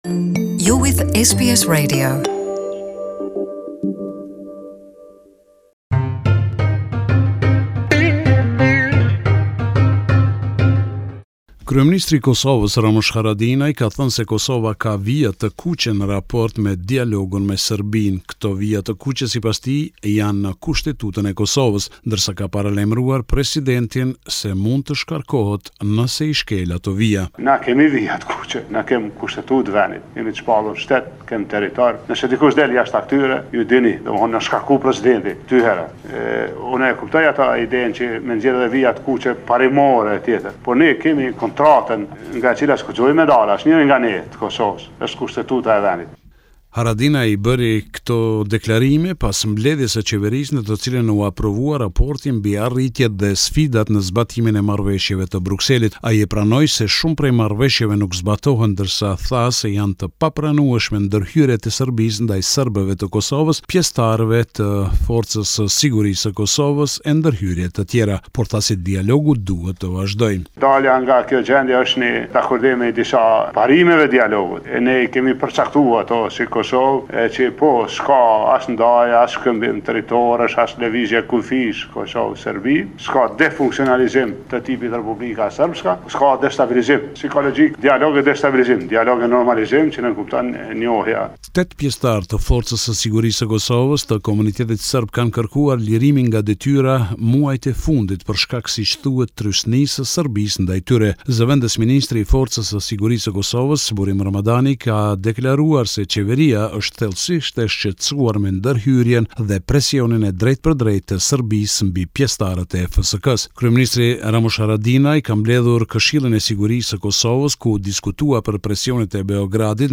This is a report summarising the latest developments in news and current affairs in Kosovo